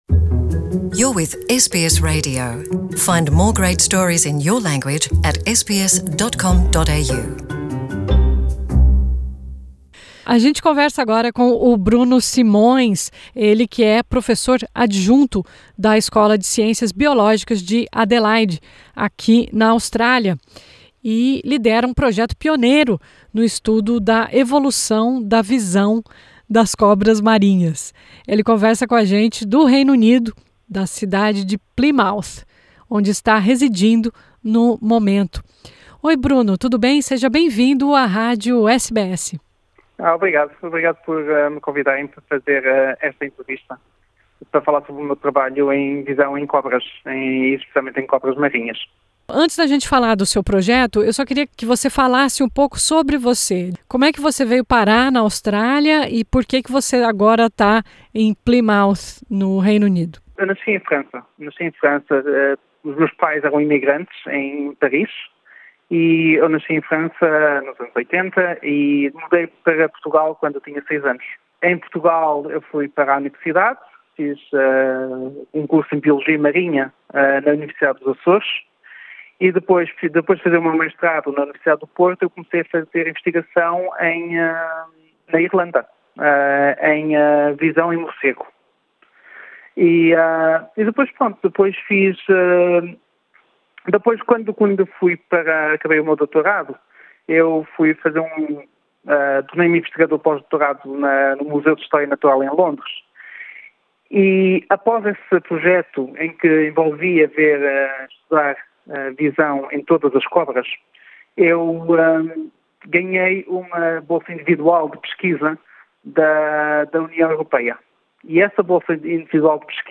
A pesquisa sugere que a visão das cobras marinhas foi modificada geneticamente ao longo de milhões de gerações, auxiliando na adaptação a novos ambientes. O estudo também revelou que as cobras estão enxergando melhor as presas - e os predadores - bem abaixo da superfície do mar. Veja abaixo os principais trechos da entrevista.